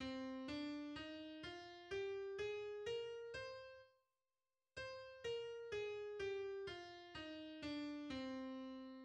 Äolisch (reines Moll)
Tonleiter_c-äolisch-moll.mid.mp3